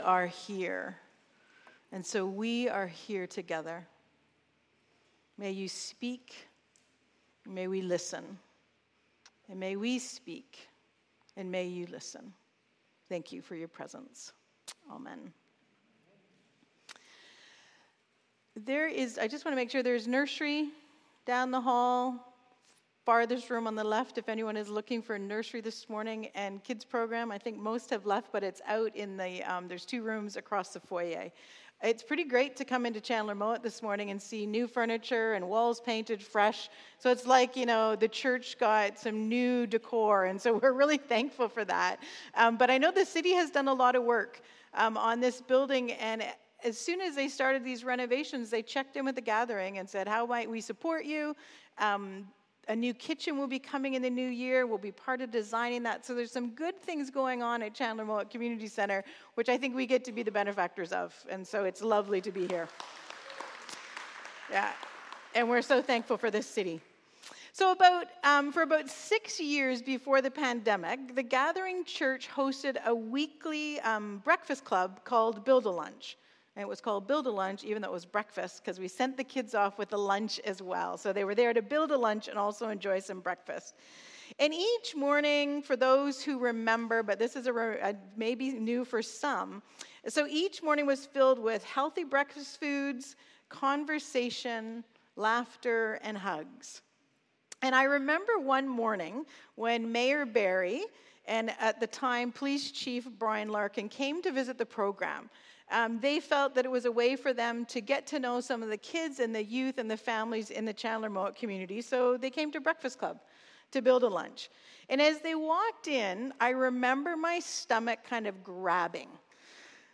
Sermons | The Gathering Church
This Sunday morning we'll hear stories from our Chandler Mowat neighbours about the impact of the church as a vessel of God's love and justice through the formation of relationships while sharing meals and time together.